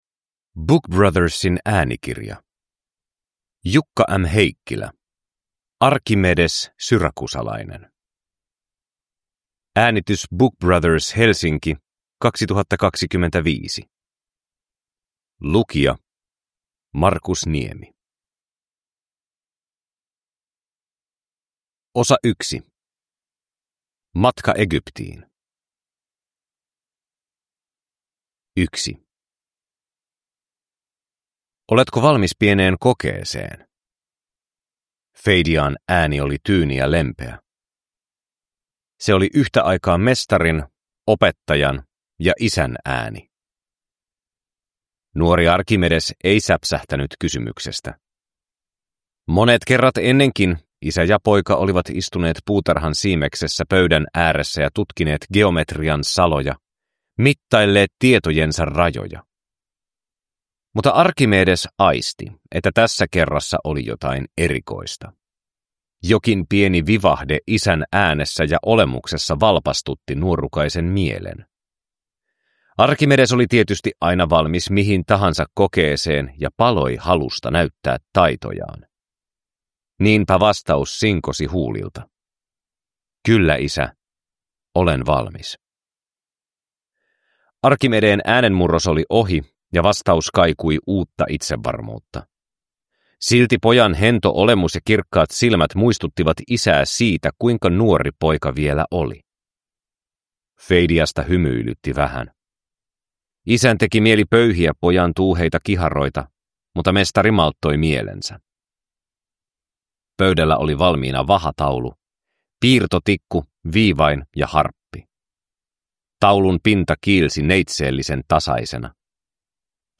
Arkhimedes syrakusalainen – Ljudbok